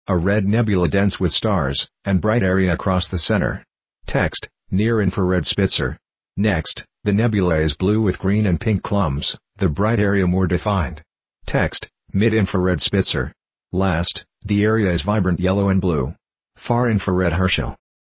• Audio Description